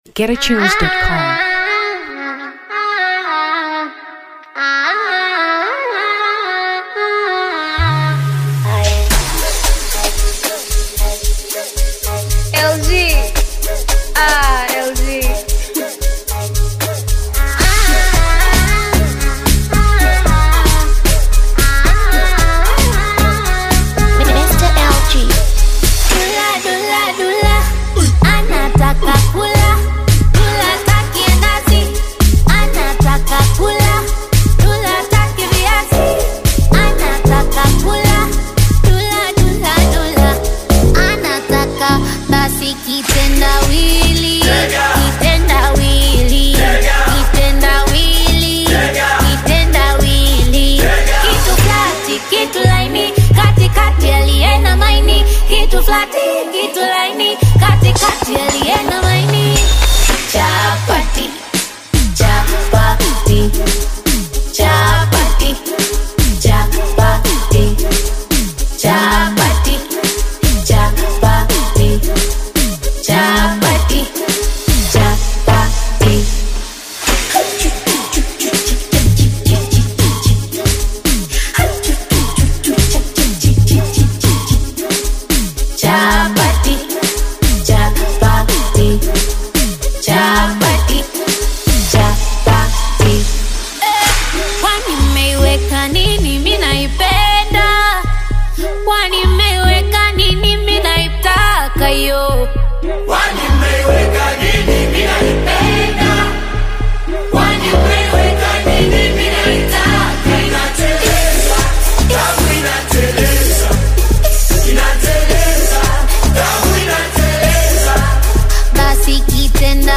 Amapiano 2023 Tanzania